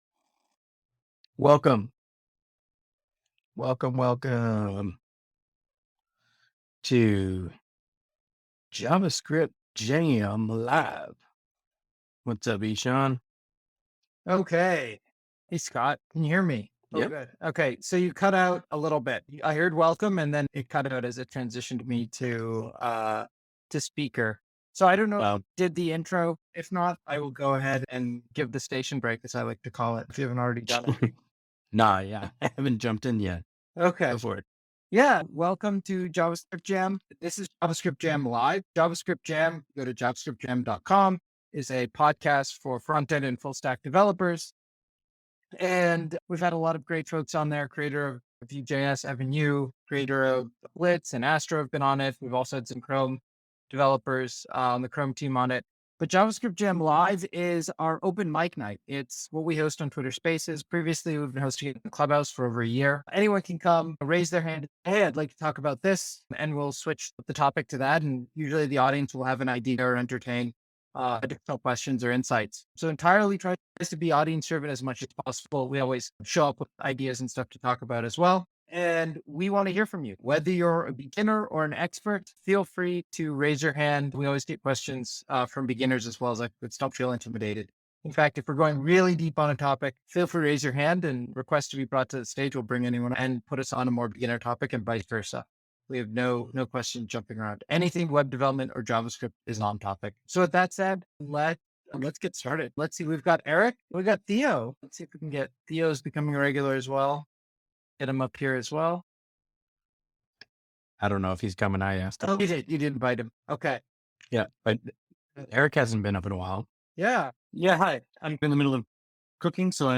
Episode Summary In this episode, the hosts and guests embark on a wide-ranging conversation that touches on both the present and future of JavaScript development and beyond.
Chapters 00:00 - Introduction and Beginning of Episode In this opening segment, the hosts greet the audience and outline the format of JavaScript Jam Live, an open mic-style discussion where community members can chime in at any time.